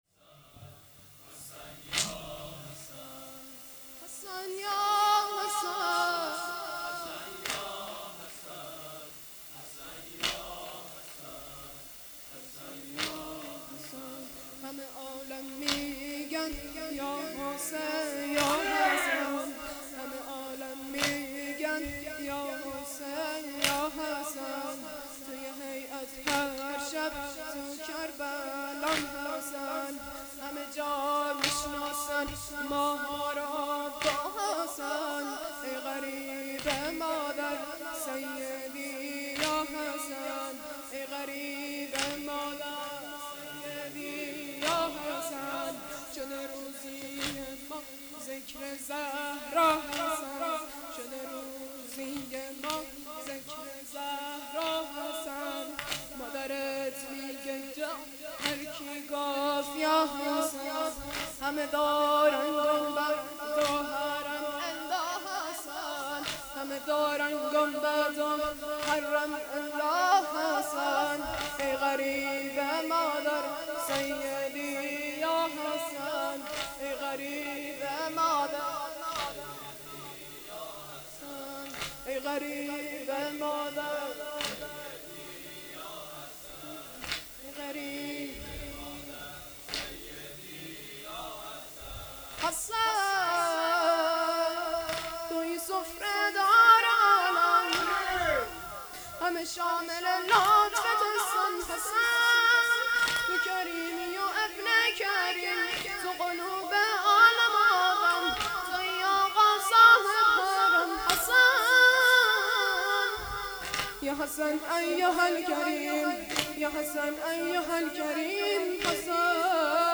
ویژه برنامه آخر ماه صفر